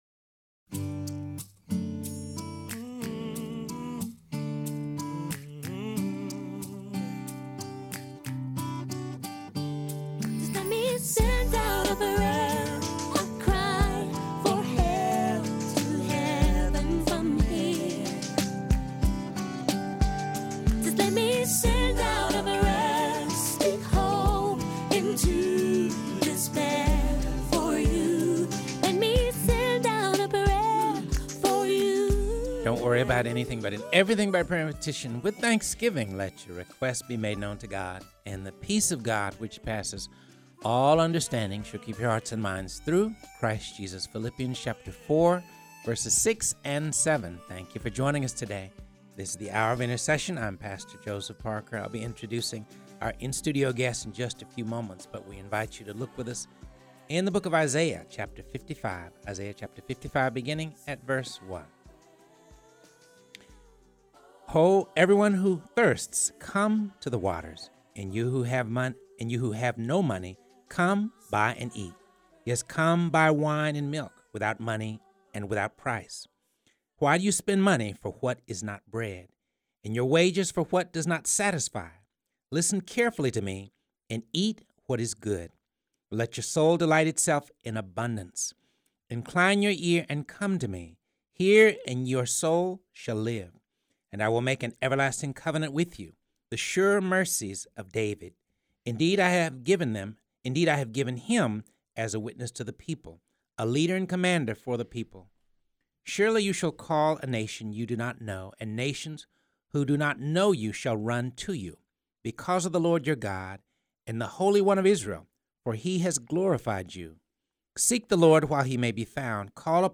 in studio to talk about their ministry to those with addictions and those recently released from prison.